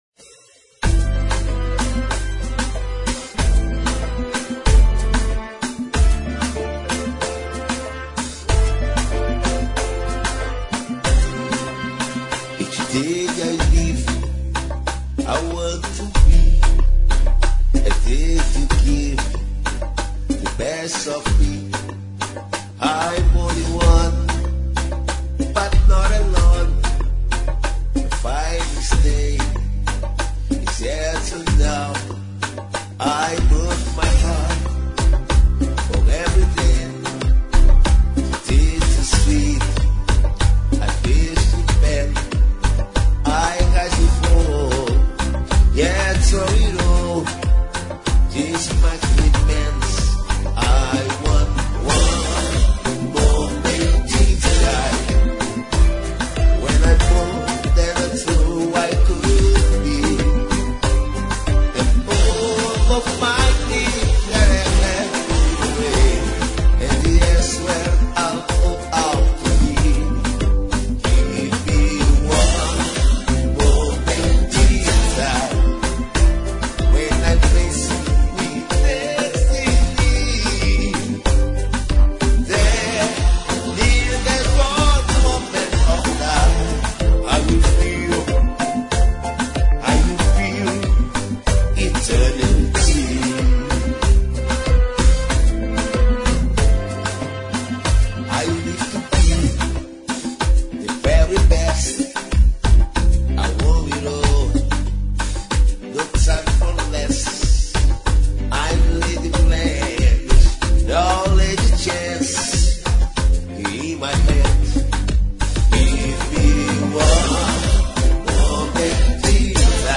Kizomba Para Ouvir: Clik na Musica.